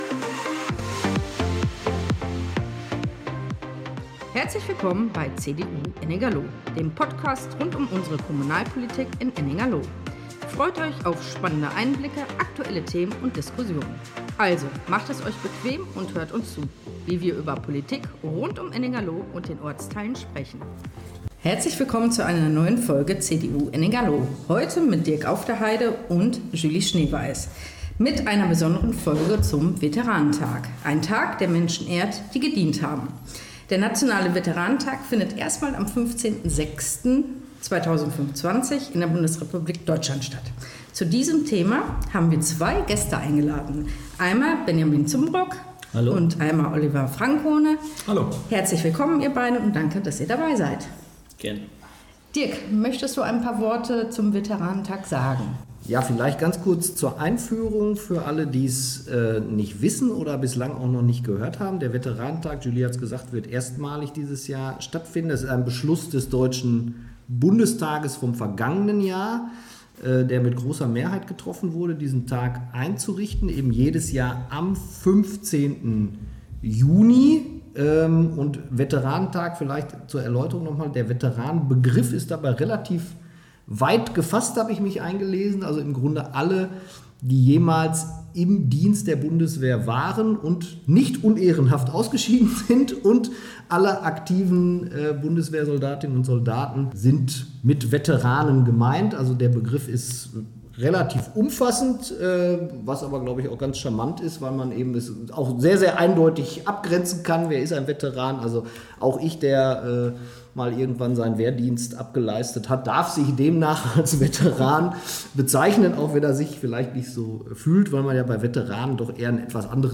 Lively-Instrumental